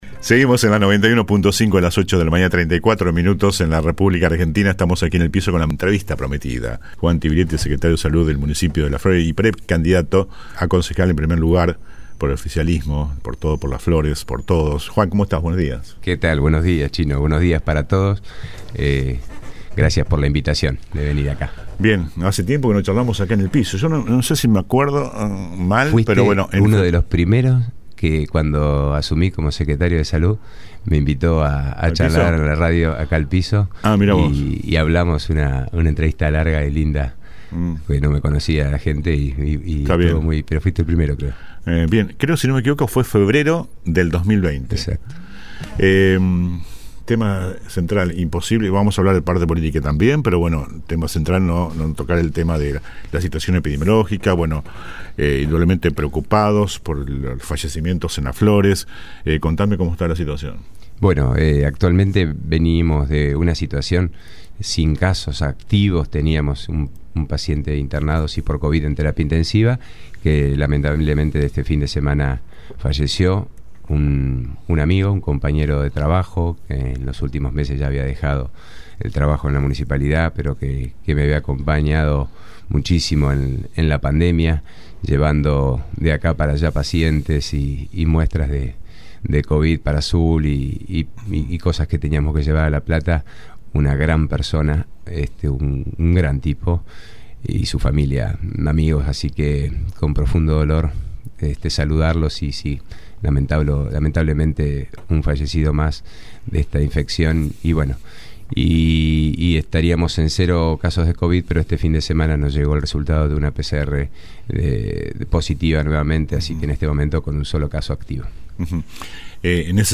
ENTREVISTA COMPLETA A JUAN TIBILETTI
juan-tibiletti-en-vivo.mp3